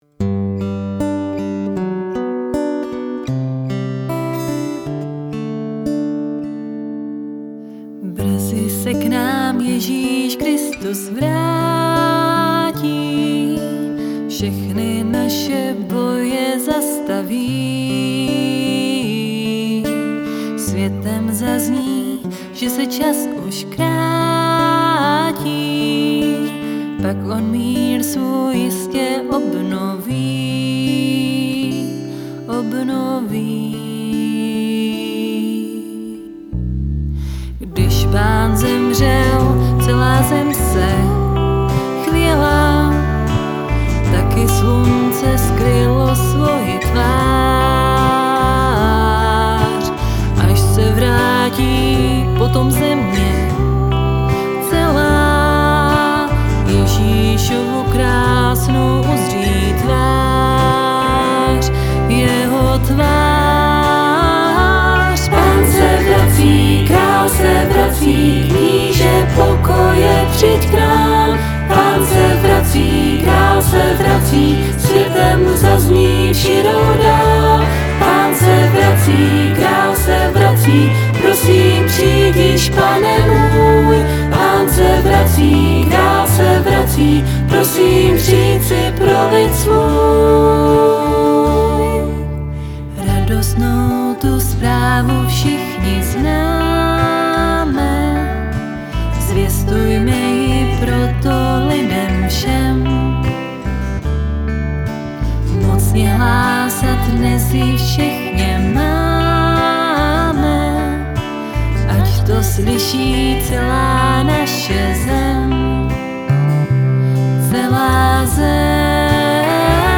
Tahle verze má pro mě až moc halu.